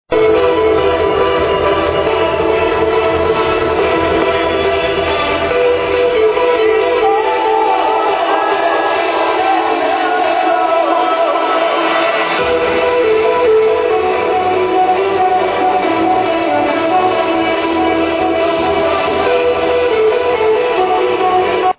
Defeintely trance but I have no idea timeline.